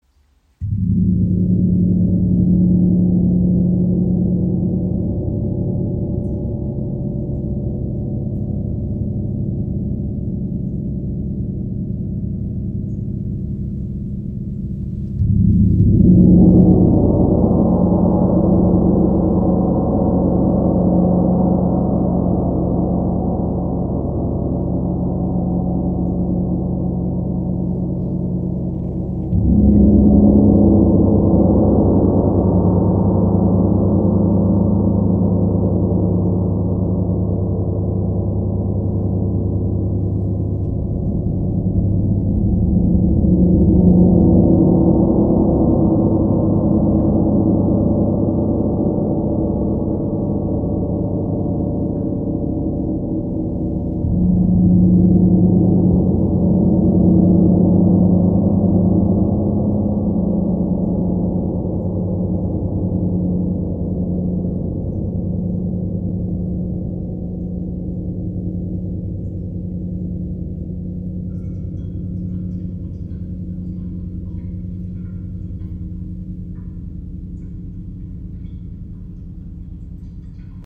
Klangbeispiel
Sein Klang ist tief, ausgewogen und reich an Obertönen, die eine Atmosphäre zwischen Erdentiefe und kosmischer Weite erschaffen. Mit Reibungsschlägeln wie den B Love Flumies entstehen sanfte, traumhafte Wal- und Delfinsounds.